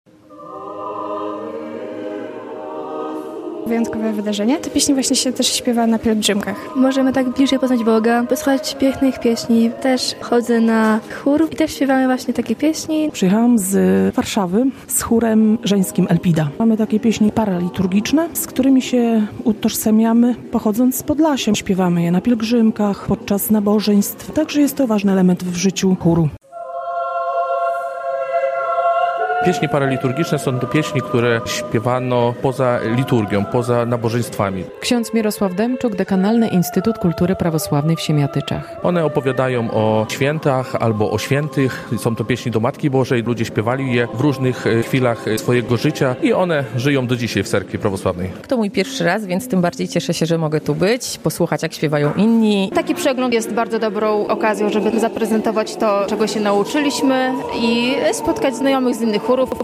Radio Białystok | Wiadomości | Wiadomości - Siemiatycze.